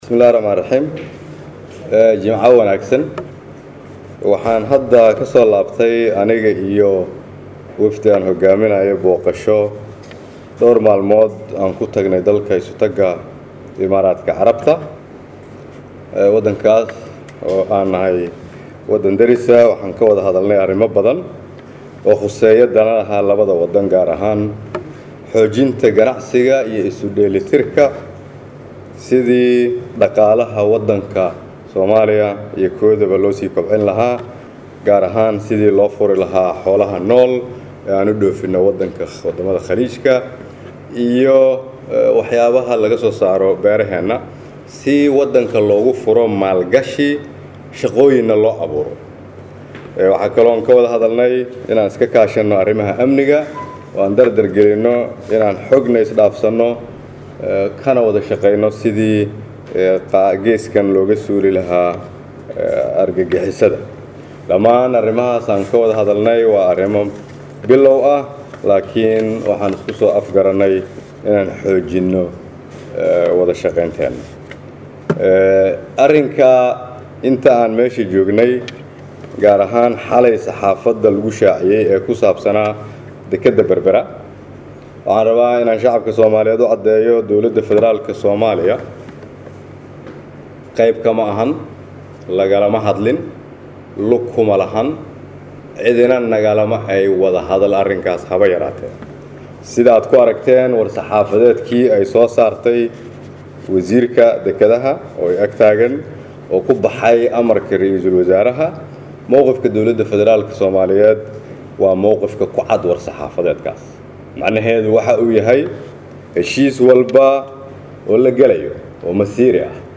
Halkaan hoose ka dhageyso Codka R/wasaare kheyre